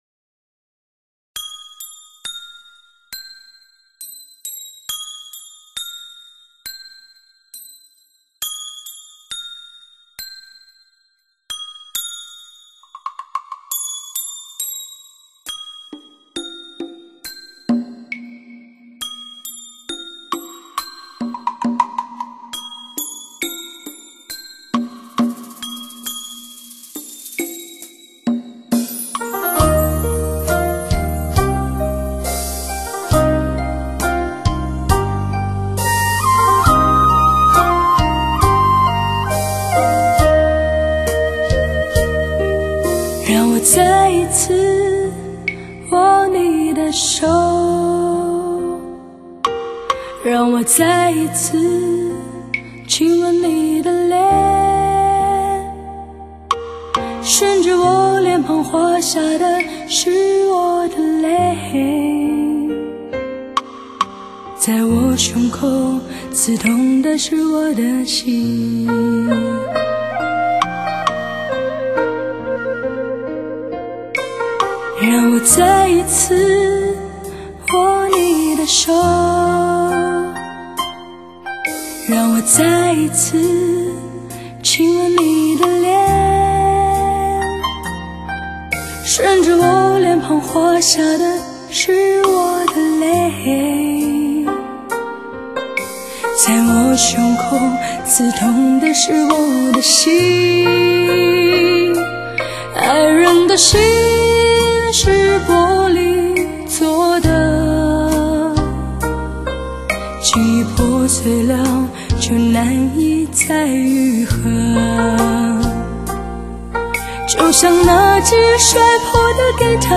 （试听为低品质wma，下载为320k/mp3）